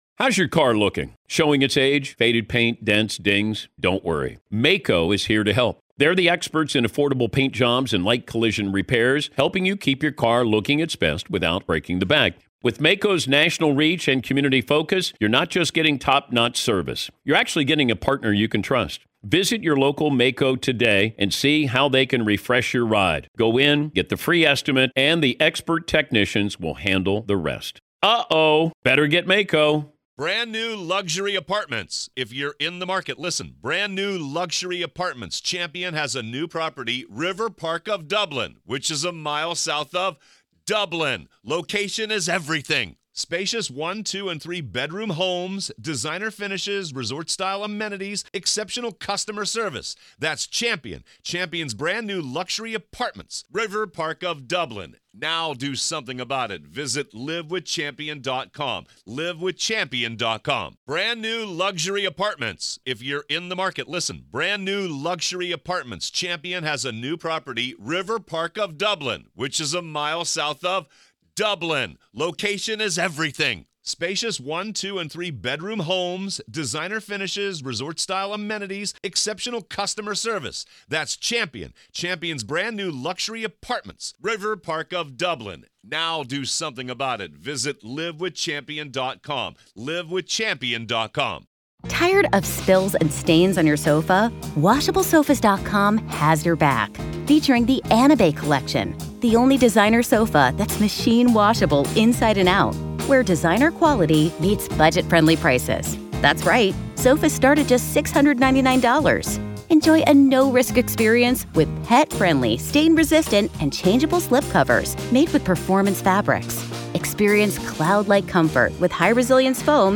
This is our continuing coverage of the Alex Murdaugh murder trial. Listen to the entire trial, hour by hour on our podcast feed so you don’t miss a single moment of testimony and evidence being brought forth as Alex Murdaugh faces first-degree murder charges in the deaths of h...